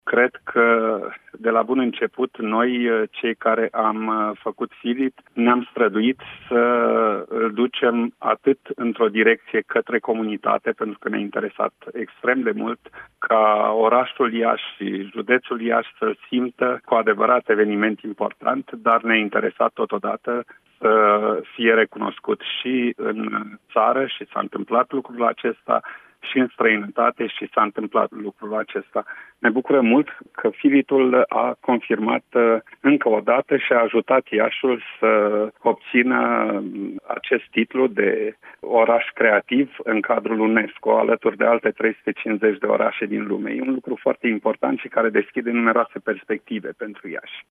Într-o intervenție pentru postul nostru de radio